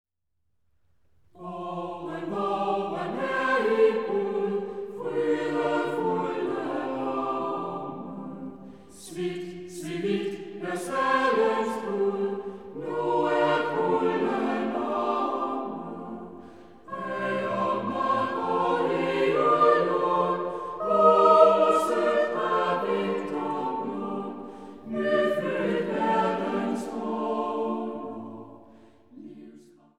har en usædvanlig smuk og smidig klang…